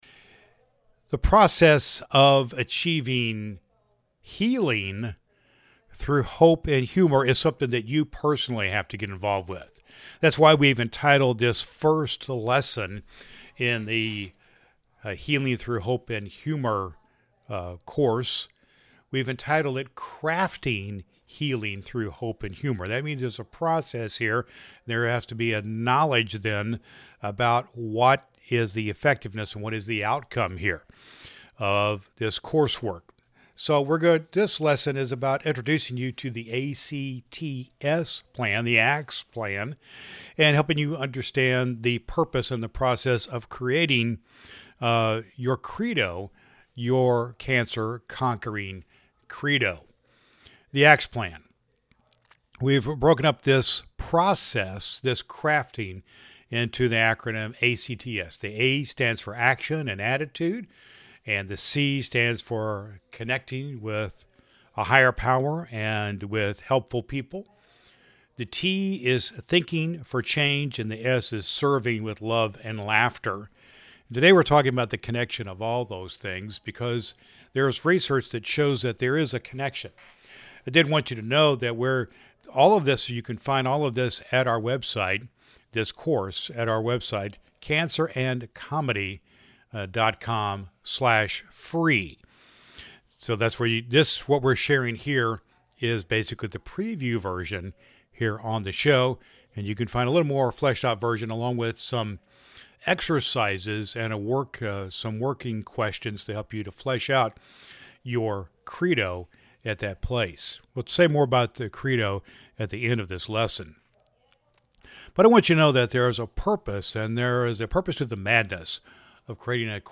The episode is punctuated with anecdotes, light-hearted jokes, and profound reflections on how laughter can serve as a balm for the emotional and physical toll of the cancer journey, ultimately encouraging listeners to embrace hope and community su